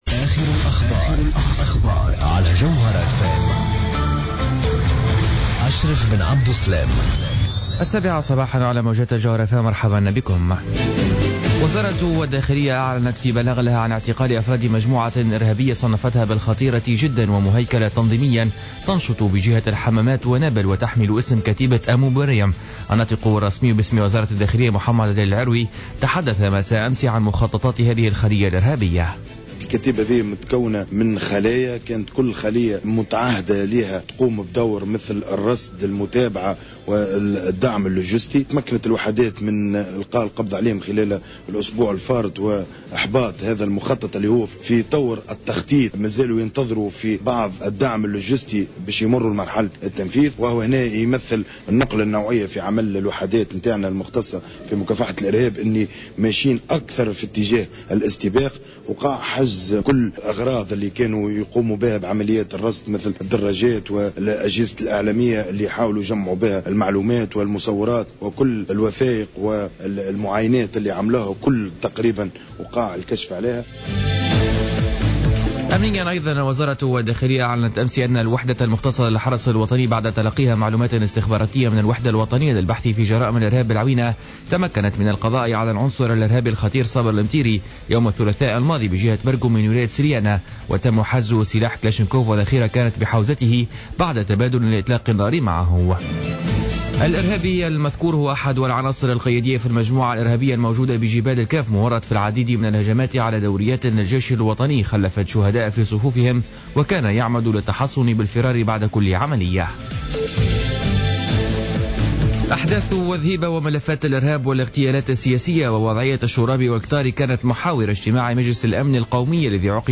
نشرة أخبار السابعة صباحا ليوم الخميس 12 فيفري 2015